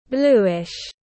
Màu phớt xanh da trời tiếng anh gọi là bluish, phiên âm tiếng anh đọc là /ˈbluː.ɪʃ/.
Bluish /ˈbluː.ɪʃ/